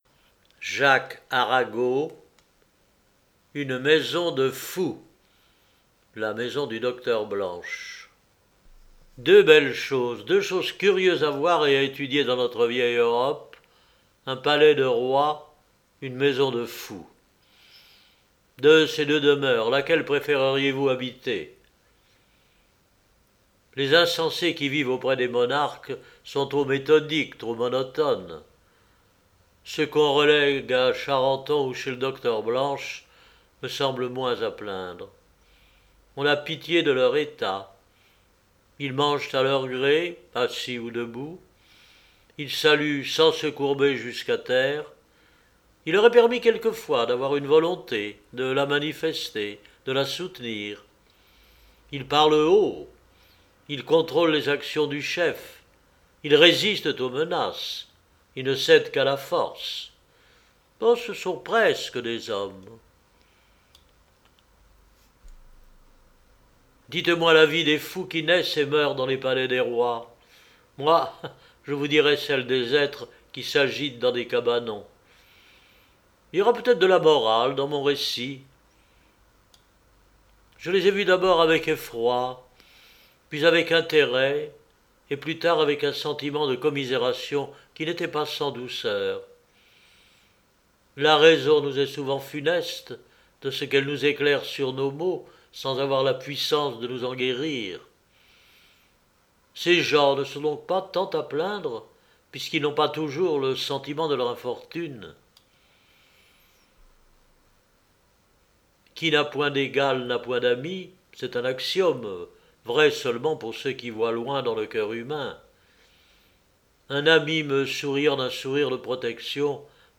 ARAGO Jacques – Livres Audio !
Genre : Nouvelles En 1821